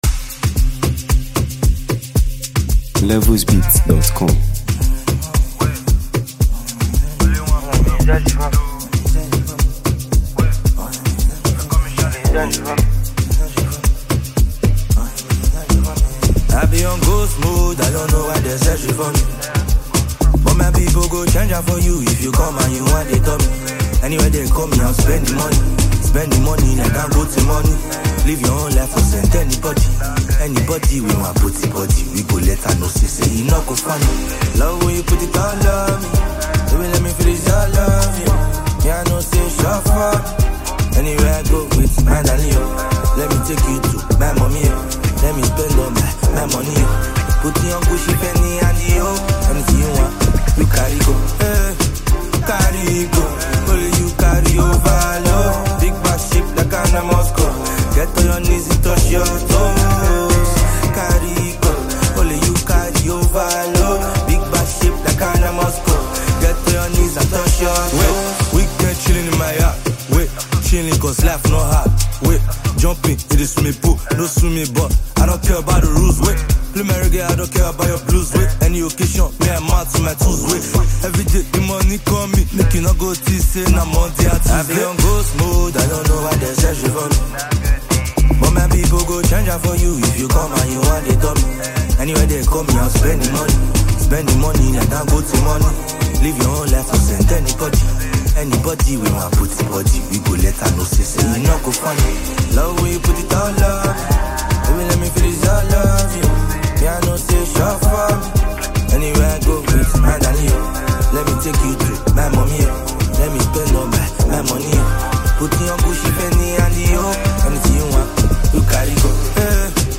Nigeria Music 2025 2:35